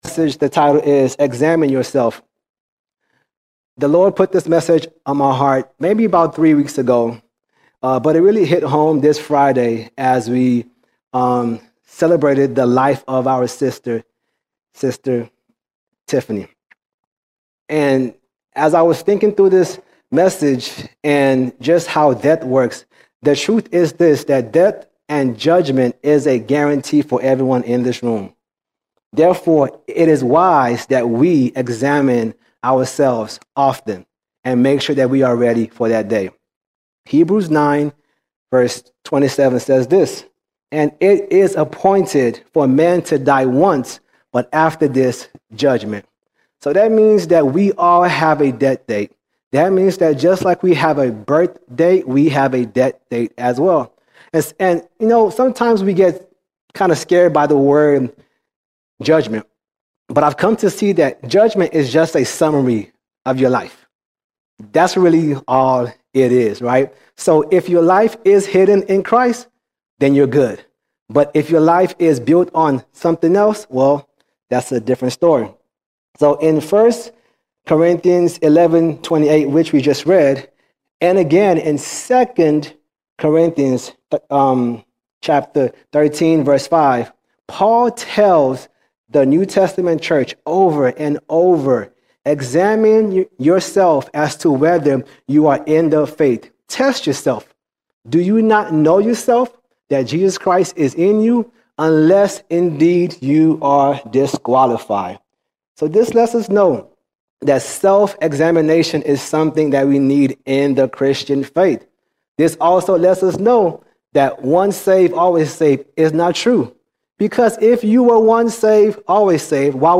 1 September 2025 Series: Sunday Sermons All Sermons Examine Yourself Examine Yourself Death and judgment are certain.